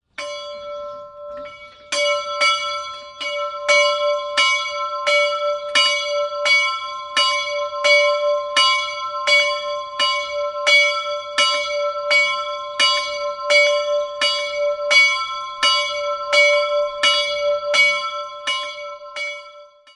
Das kleine Gotteshaus mit dem einfachen Dachreiter wurde in den Jahren 1969/70 errichtet. Einzelglocke: d''' Die Glocke wurde 1792 in Nürnberg gegossen, hat einen Durchmesser von 375 mm und trägt die Inschrift: GOSS MICH ARTILLERI LIEUTENANT STUMM NURNBERG 1792